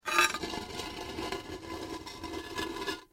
moving-stone-platform.mp3